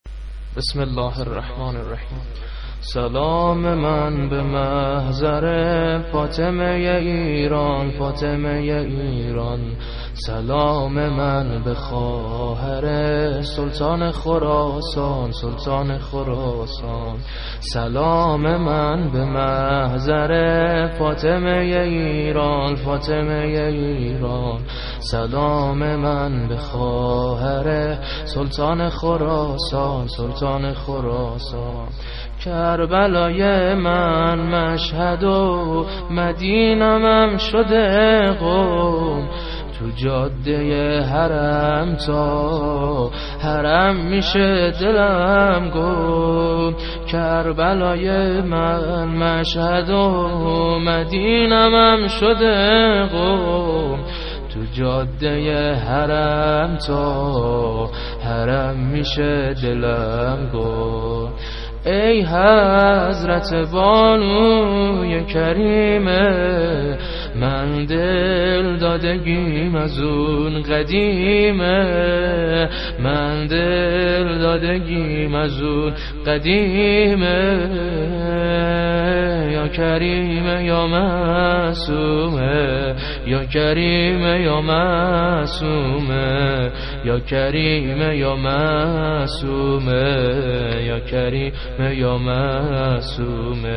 شور ، حضرت معصومه سلام الله علیها -( سلام من به محضر ( فاطمه ی ایران) )